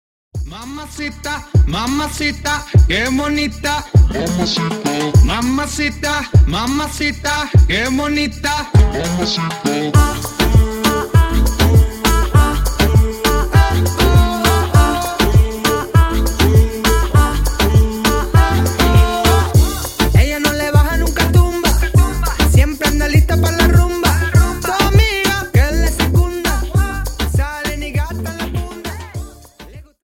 Dance: Samba